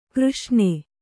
♪ křṣṇe